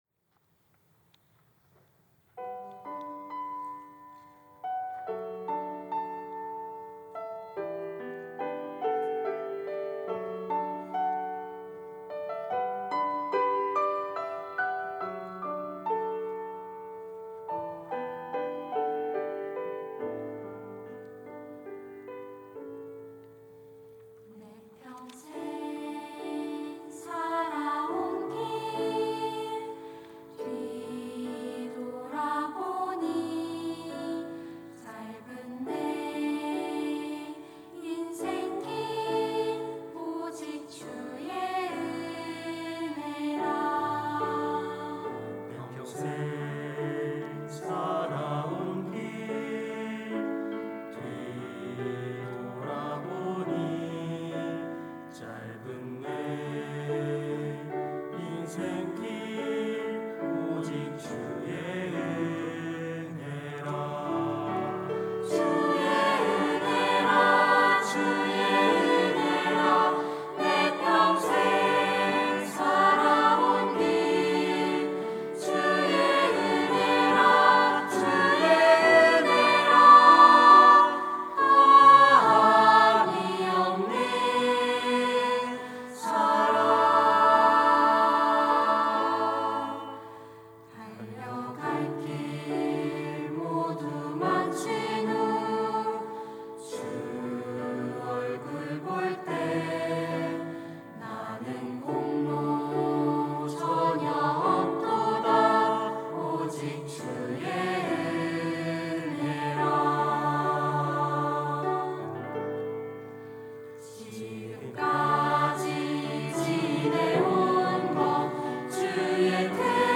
특송과 특주 - 주의 은혜라
청년부 카이노스 찬양대